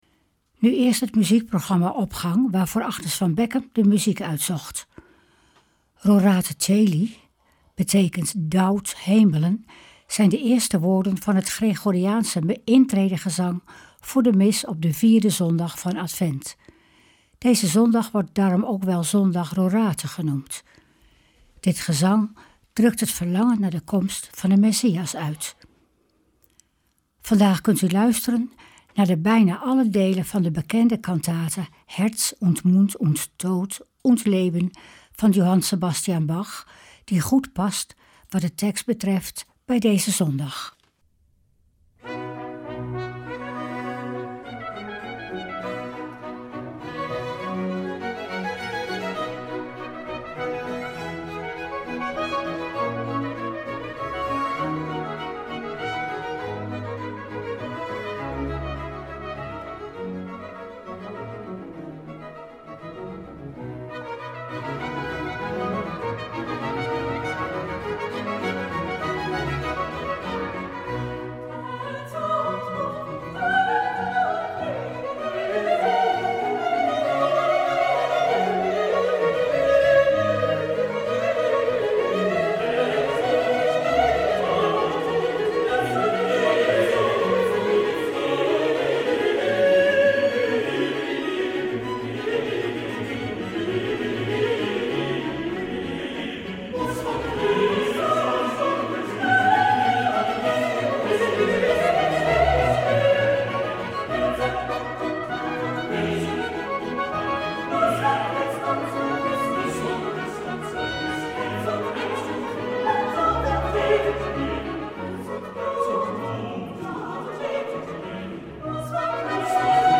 Opening van deze zondag met muziek, rechtstreeks vanuit onze studio.
Vandaag kunt u luisteren naar de bekende cantate BWV 147, Herz und Mund und Tat und Leben, van Johann Sebastian Bach, die goed past wat tekst betreft bij deze zondag.
De Opgang van deze zondag wordt afgesloten met een oud lied passend bij zondag Rorate en dat u vandaag in het Duits hoort maar ook in het liedboek vermeld staat onder lied 437, Kom tot ons, scheur de hemelen Heer.